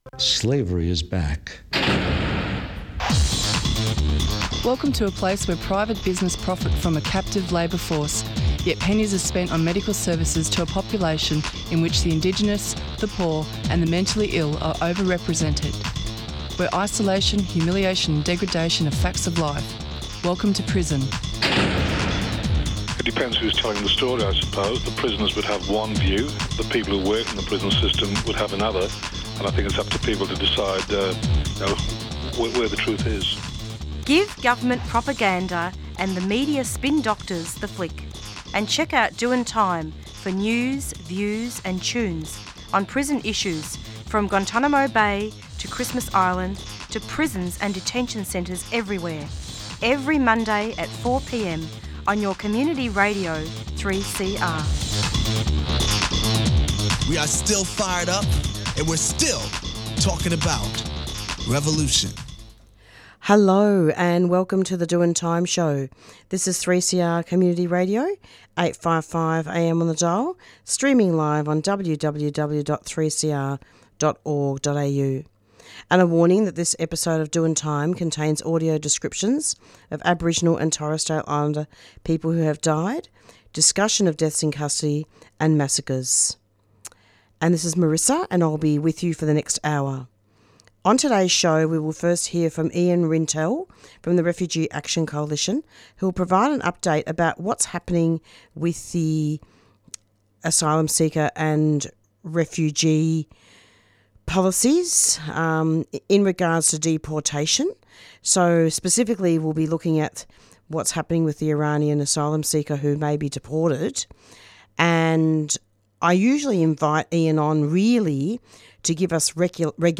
Then, there was an interview